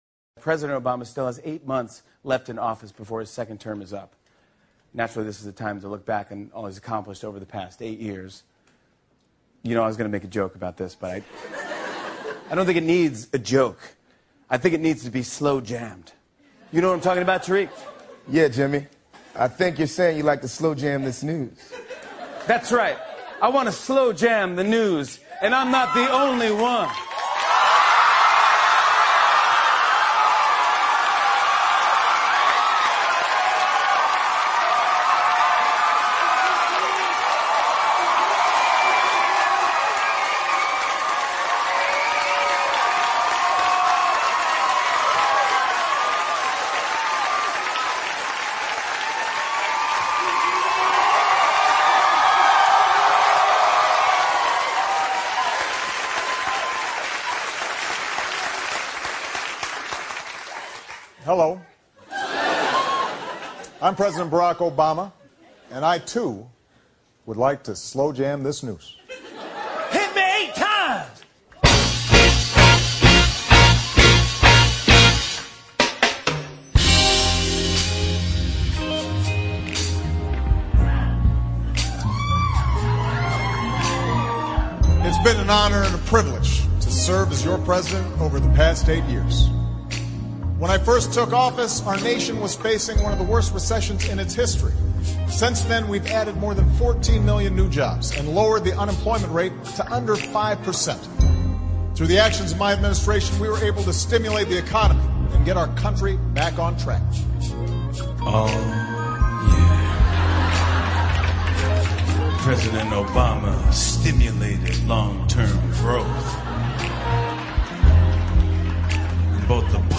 欧美名人演讲 第76期:奥巴马柔情演绎总统业绩回顾(1) 听力文件下载—在线英语听力室